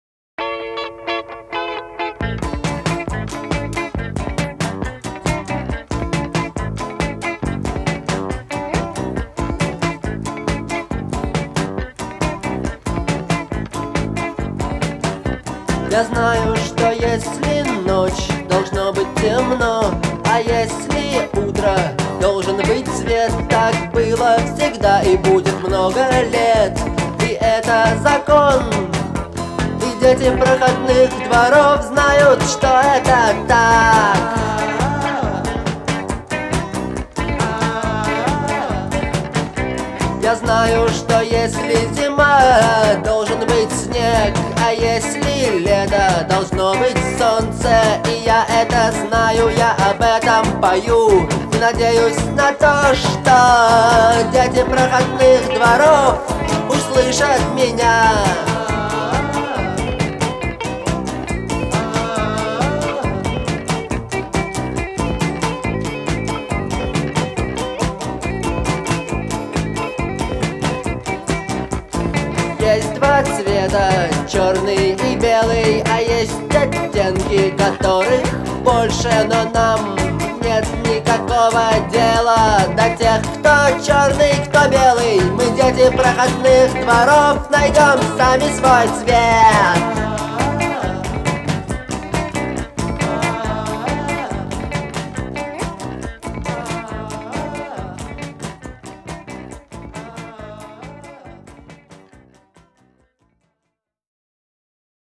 Звучание отличается характерным гитарным рифом